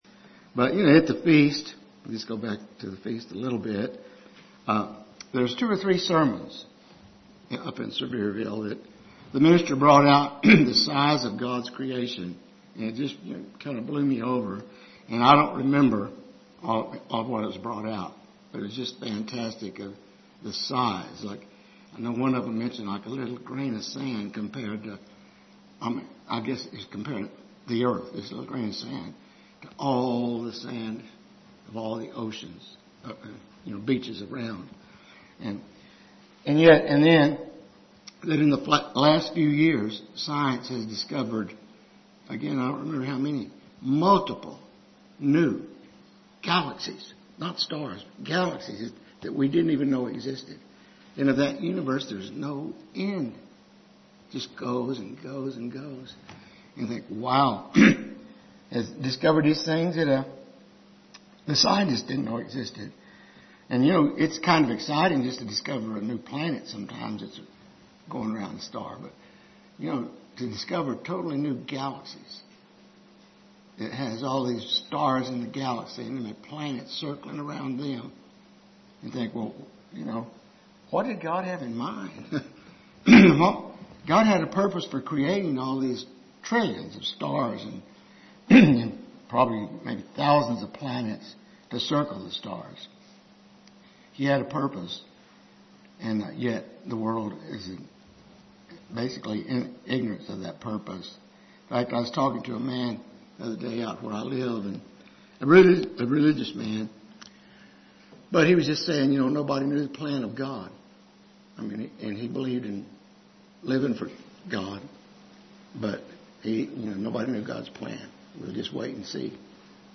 UCG Sermon Studying the bible?
Given in Lubbock, TX